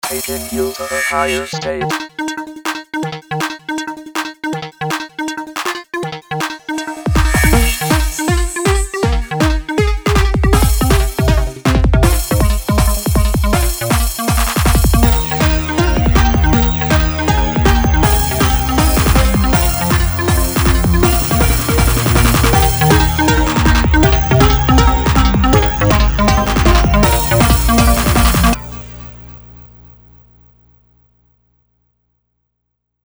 Robot voice